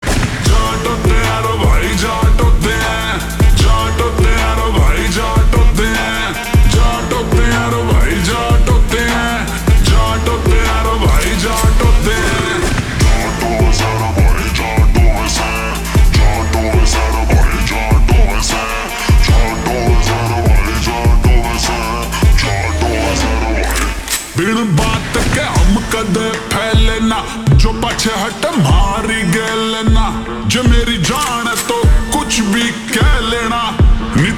Haryanvi Songs
• Simple and Lofi sound
• Crisp and clear sound